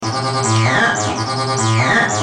Sons et loops gratuits de TB303 Roland Bassline
Basse tb303 - 44